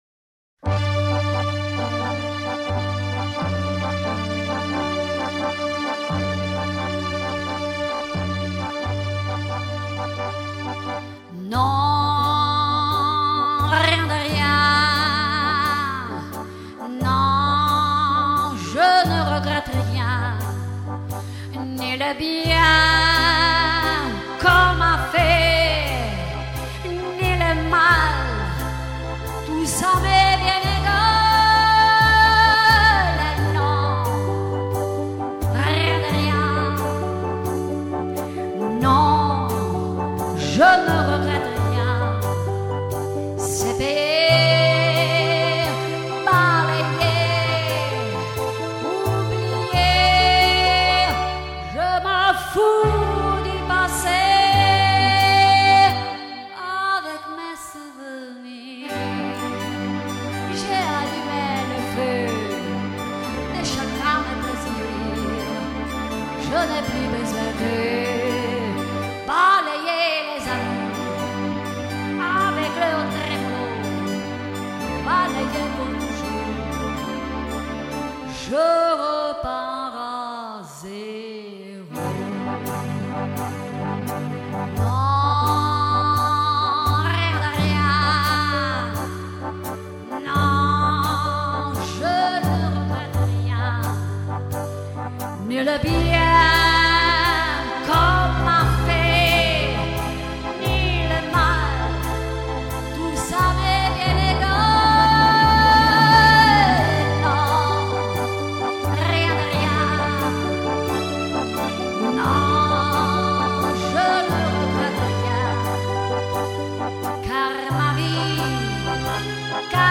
chansonů
Ideální na rauty a pro komorně laděné příležitosti.